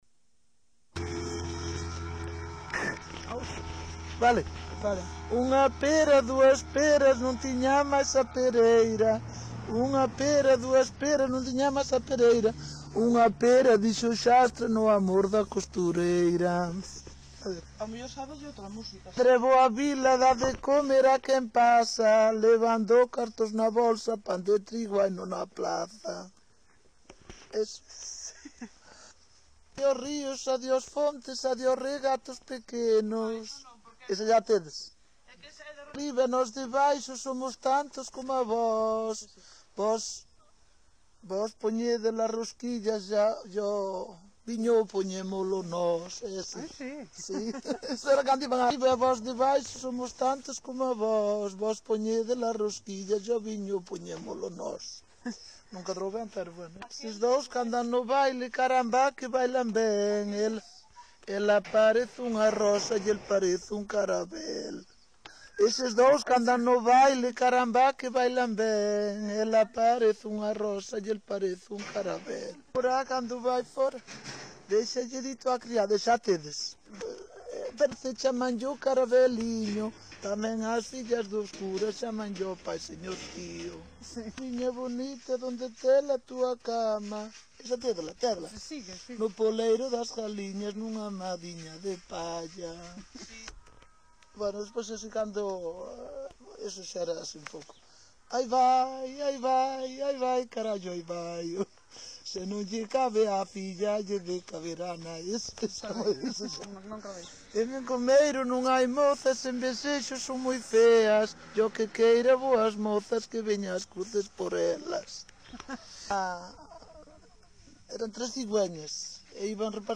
Coplas -
Id: 3604 Coleccion: Schubarth-Santamarina Ano de recolla: 1980 Concello: Vila de Cruces.